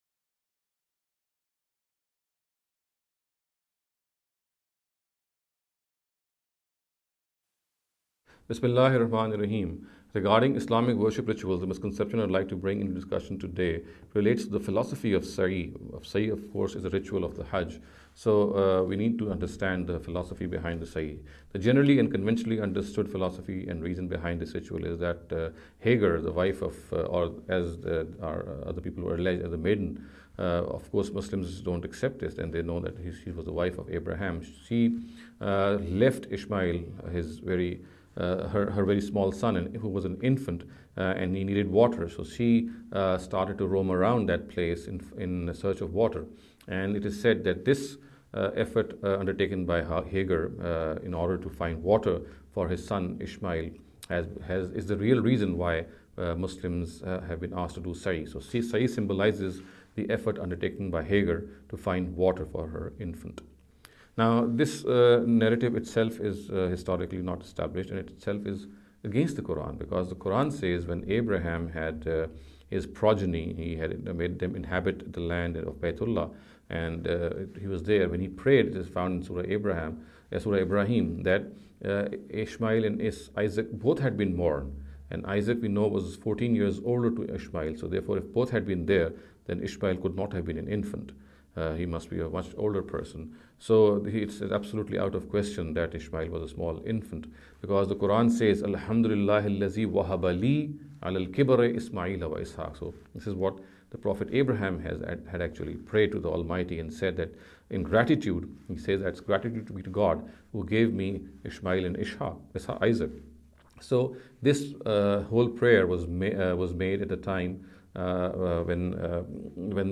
This lecture series will deal with some misconception regarding the Islamic Worship Rituals.